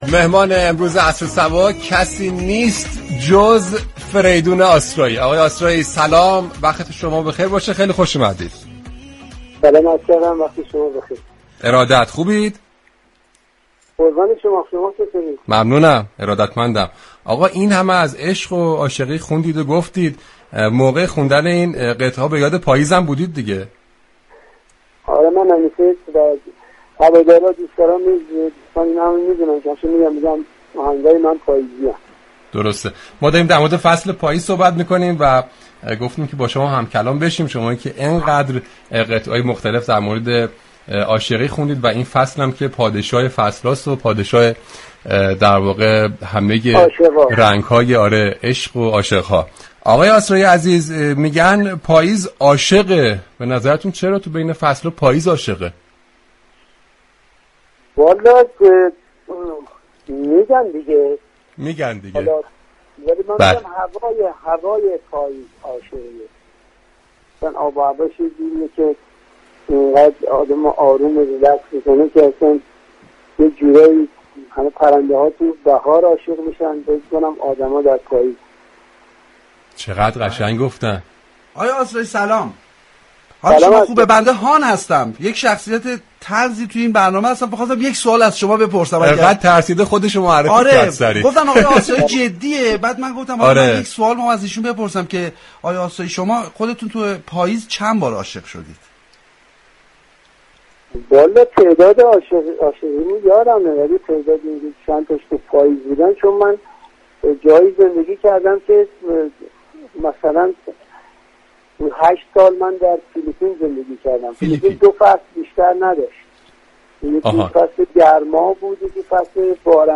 فریدون آسرایی: در گفتگو با رادیو صبا توضیح داد، هواداران من معتقد اند آهنگ های من عاشقانه و پاییزی اند.